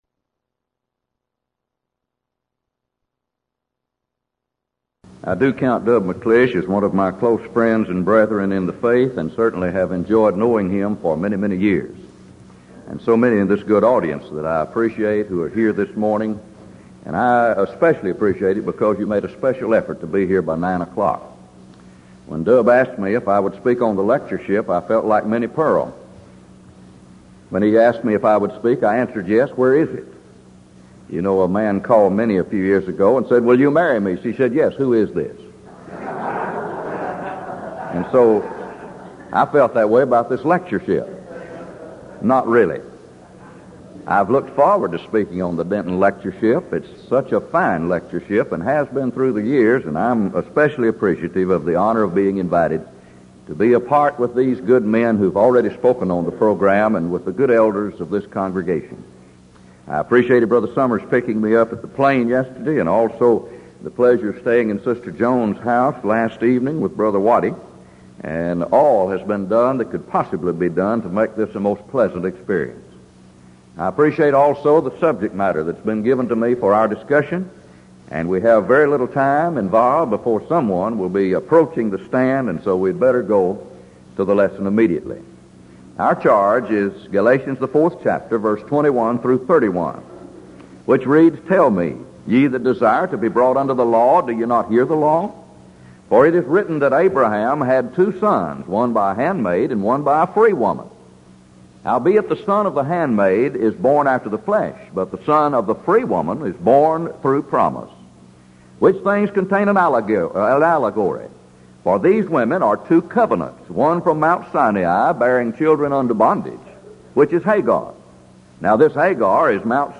Event: 1986 Denton Lectures Theme/Title: Studies in Galatians